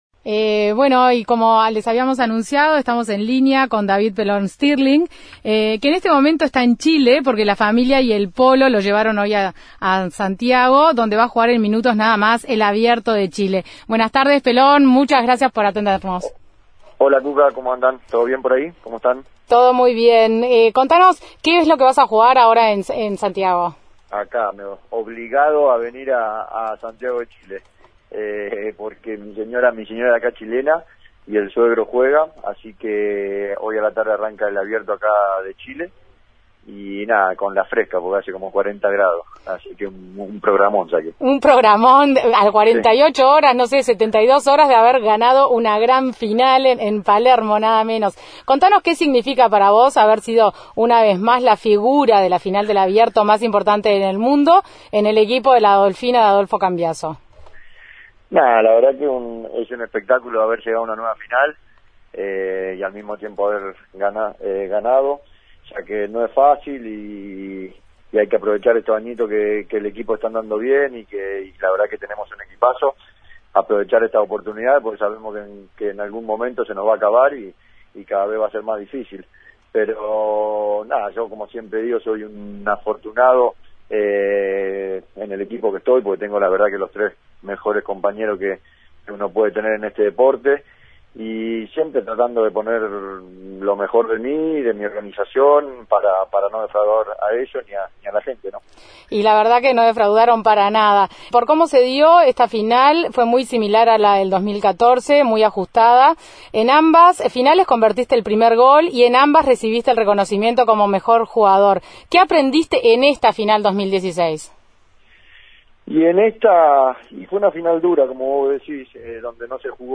En la previa a su debut en el Abierto de Chile, integrando el equipo de Verfrut (equipo sensación de la temporada que tiene como objetivo de ganar la Triple Corona) conversó con Dale que es Tarde.